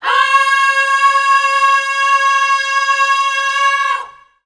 Index of /90_sSampleCDs/Voices_Of_Africa/LongNoteSustains
18_11_Ooo_Db.WAV